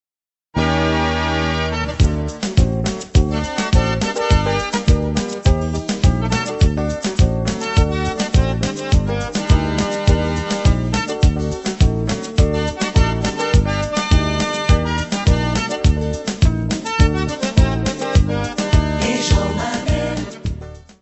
Descrição Física:  1 disco (CD) (48 min.) : stereo; 12 cm